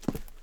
Footstep Concrete Walking 1_01.wav